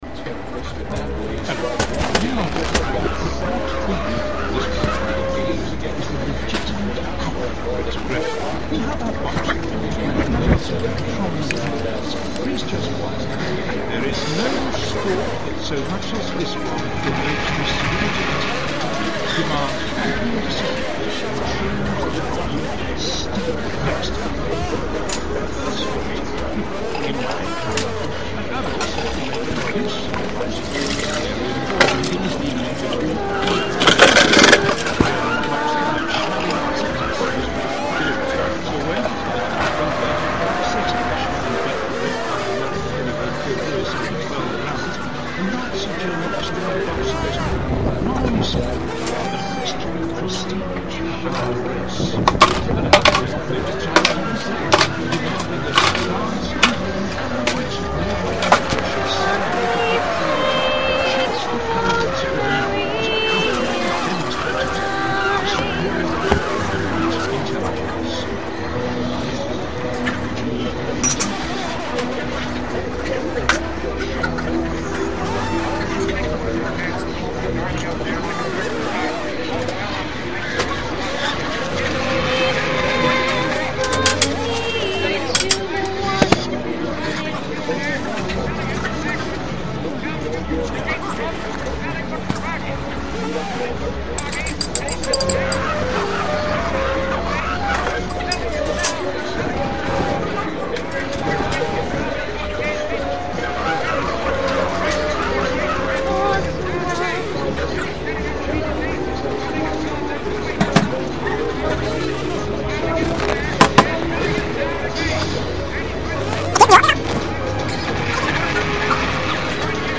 It was a long distance collaboration by the community working together on the SFØ Skype chat. To some it may sound like a random collection of noises, but I hear beauty in it.